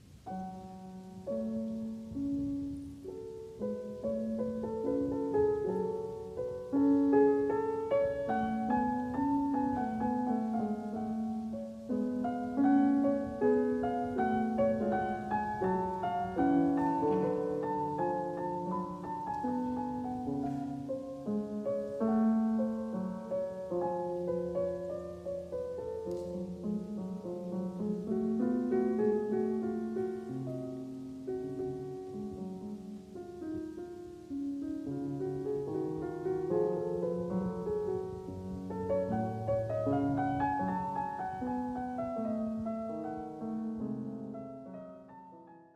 Uitgevoerd door Mieczyslaw Horszowski.